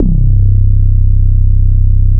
BITUME BASS.wav